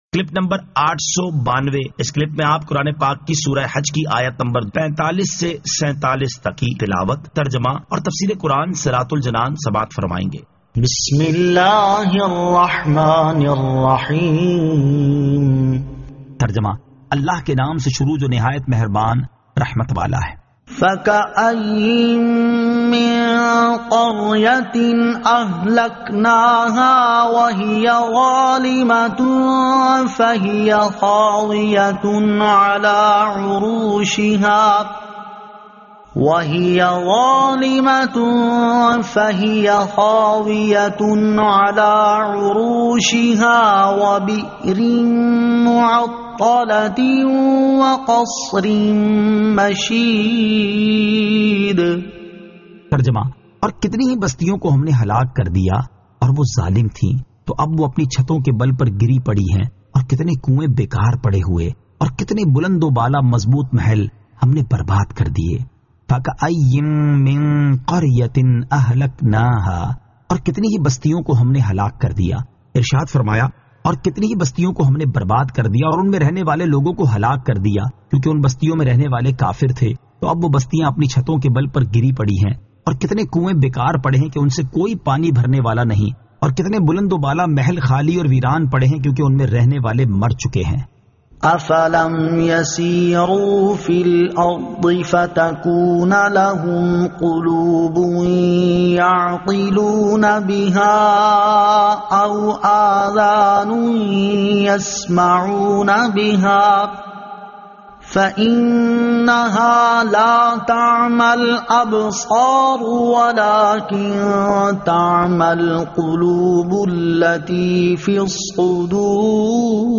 Surah Al-Hajj 45 To 47 Tilawat , Tarjama , Tafseer